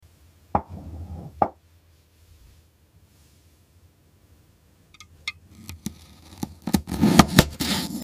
part 34 | AI ASMR sound effects free download
part 34 | AI ASMR video for cutting Lamborghini sian car